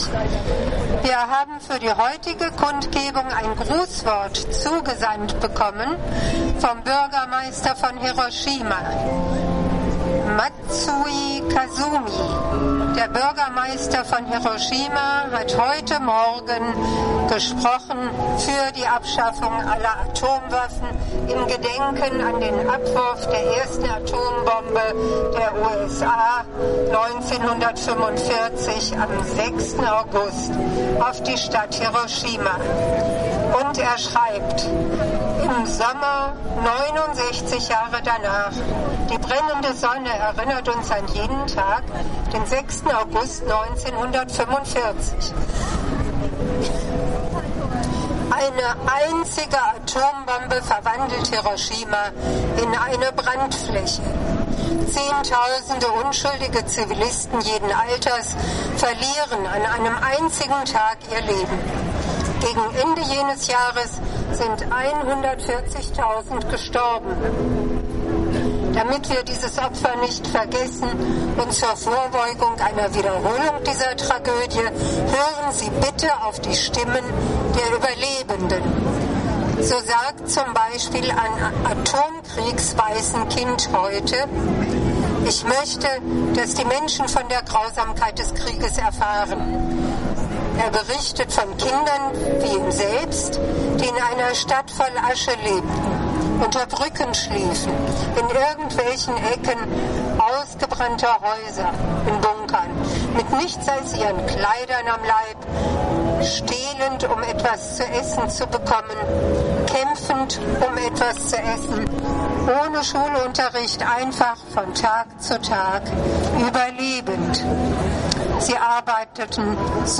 Grußwort des Bürgermeisters von Hiroshima, Kazumi Matsui.
Verstrahltes Leben Kundgebung zum Hiroshima-Nagasaki-Tag 2014, Erinnerung an die atomare Katastrophe 1945 und Mahnung wegen vorhandener Atomwaffen, auch in Deutschland
Buergermeister_Hiroshima_2014.mp3